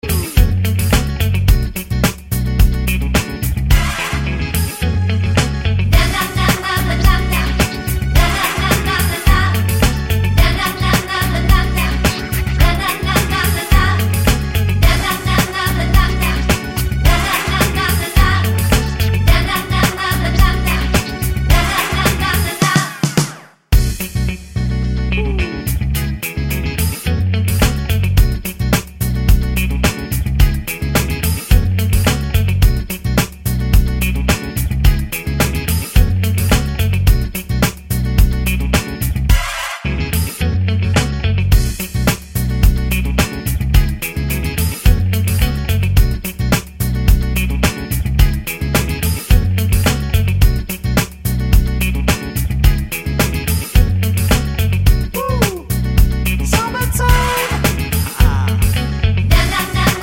no rap doubles R'n'B / Hip Hop 3:38 Buy £1.50